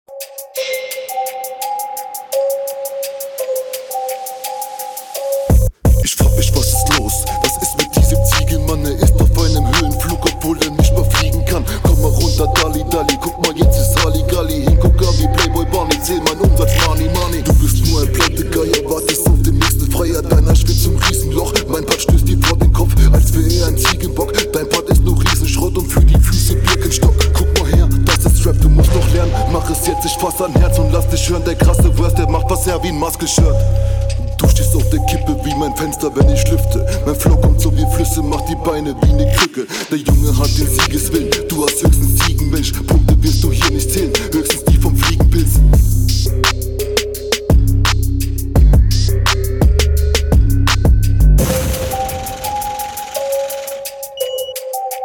Passt stimmlich direkt besser zu deiner tiefen coolen Stimme!
Stimme wieder sehr fett, diesmal auch mehr onpoint.